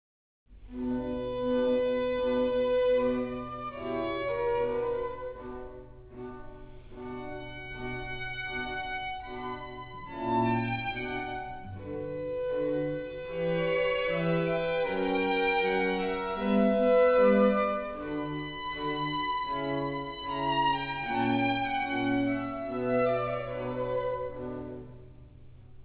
violon
alto
Les sept derniáeres paroles du Christ = String quartet